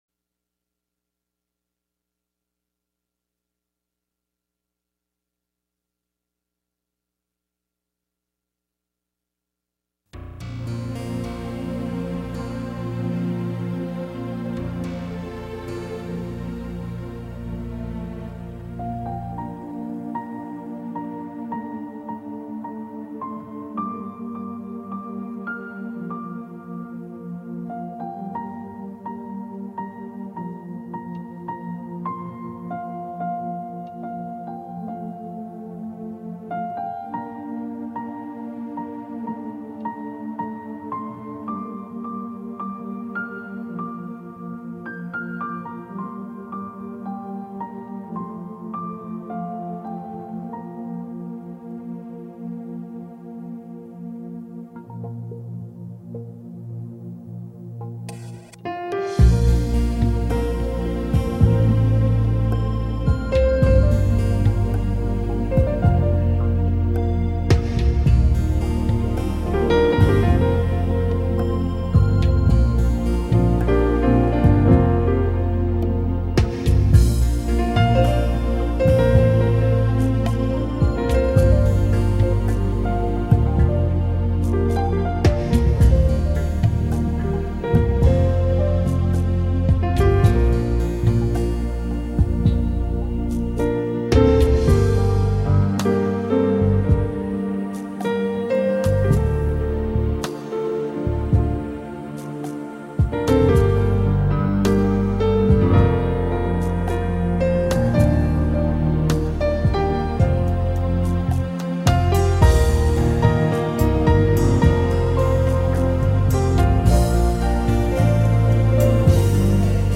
пианистка
исполняющая музыку в стиле нью эйдж и джаз.